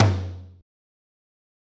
Tom 3